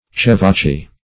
Chevachie \Chev"a*chie`\